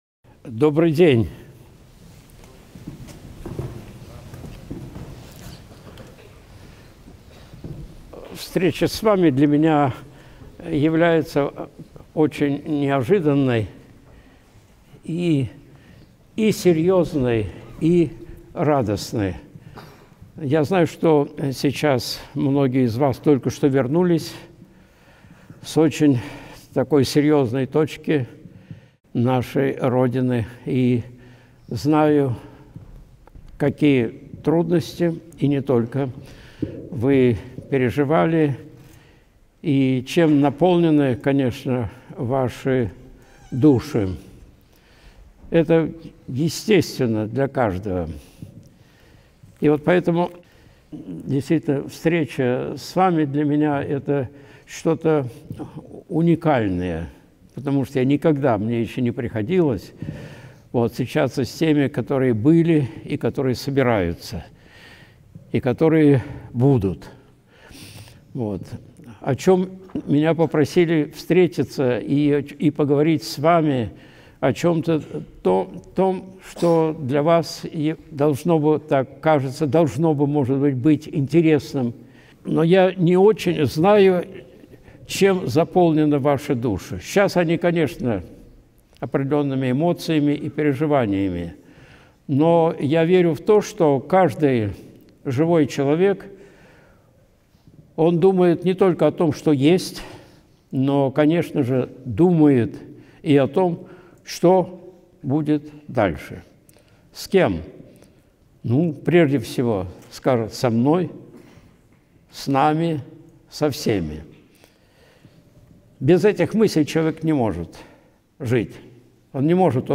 Защита Отечества. Выступление перед участниками СВО
Видеолекции протоиерея Алексея Осипова